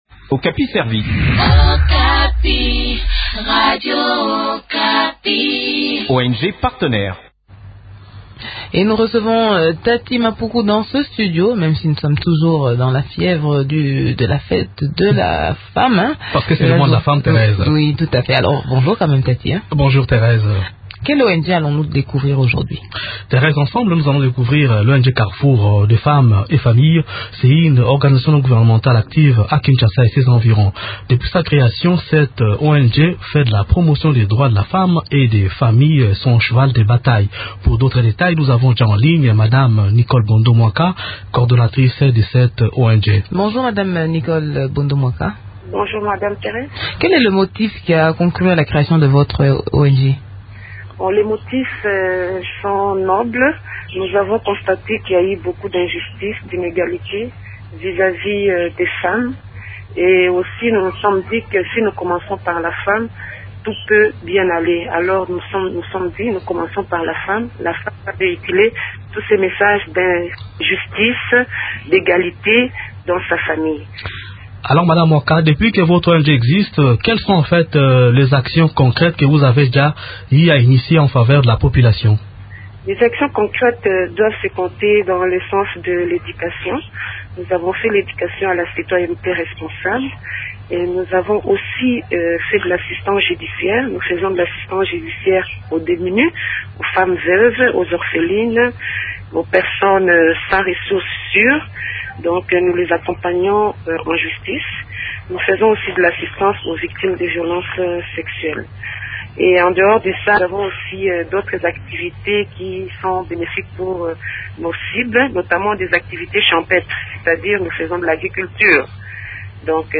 Découvrons en détail les activités de cette ONG dans cette interview